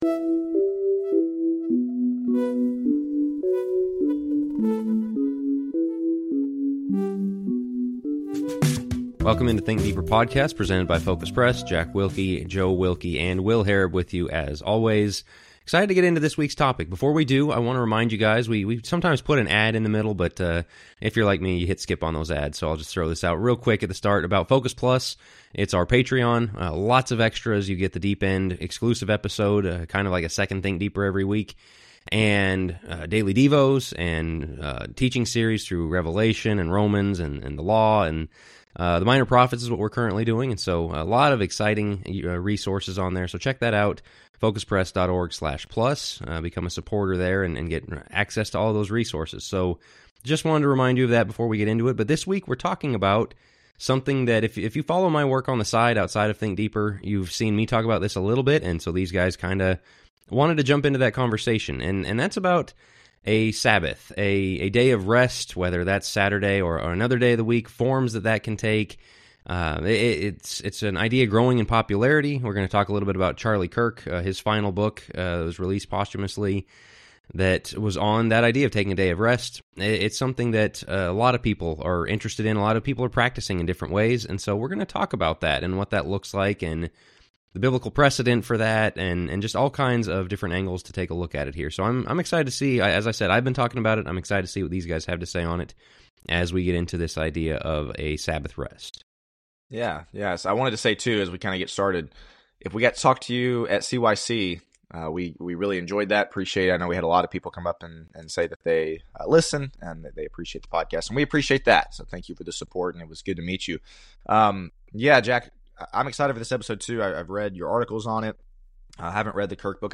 The command to observe a Sabbath day of rest as established in Exodus is not found in the New Testament... but can it still be a useful part of our spiritual lives today?What forms might that take? Join us for a discussion on the place of "Sabbath" in the Christian life.